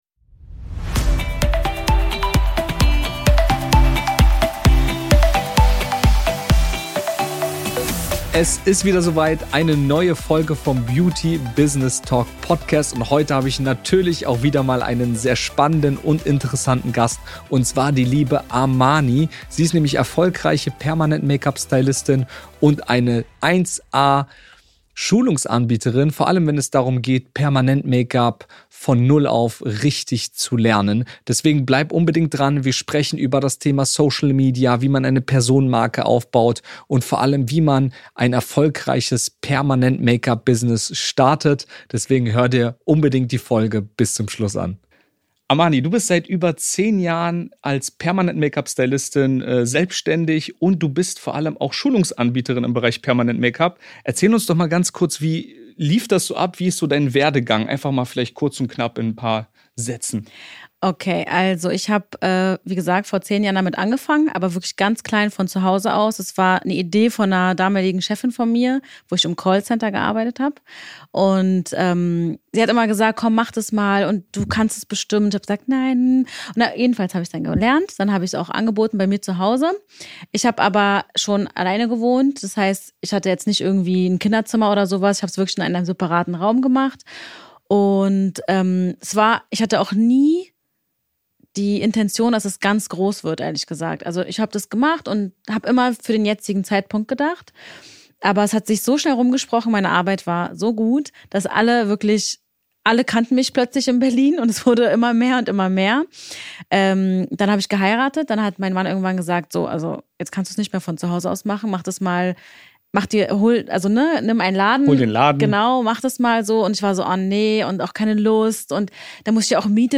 Die Macht von Social Media im Beauty-Business - Interview